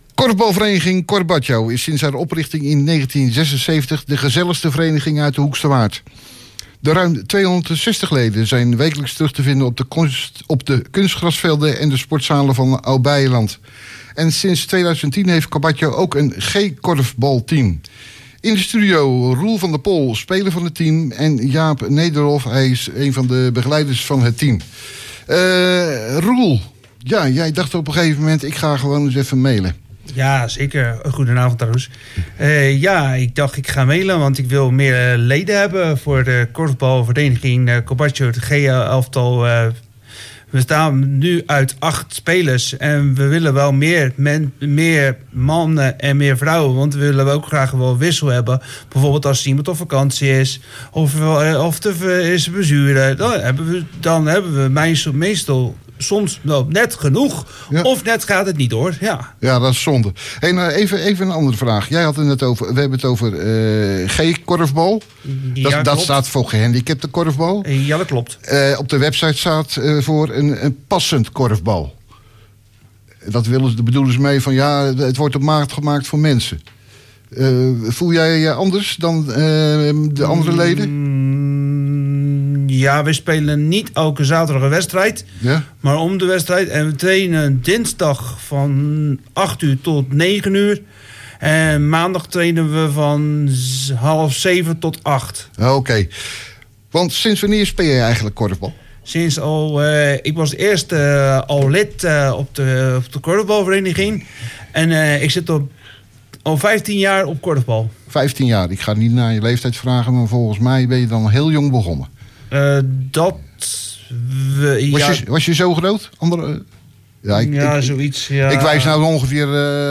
Luister naar het interview met hem in Cafe HW.